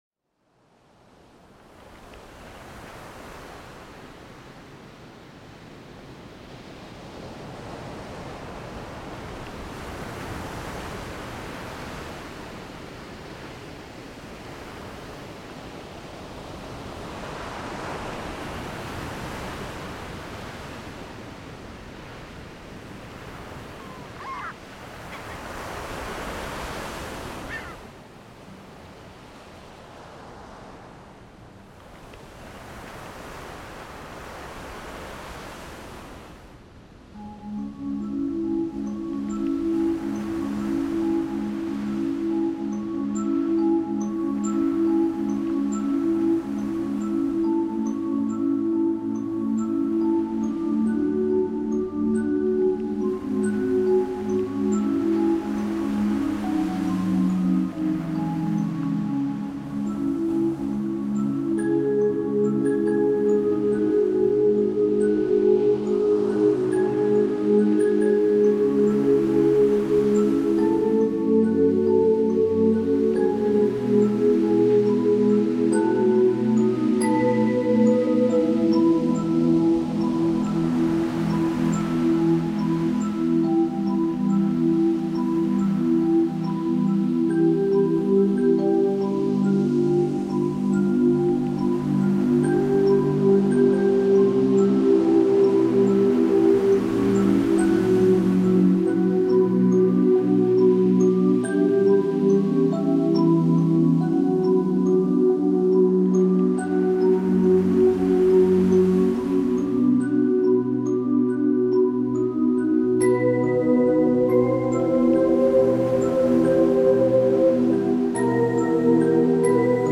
Bethoven___Lunnaya_sonata_pod_shum_morya_iPleer_.mp3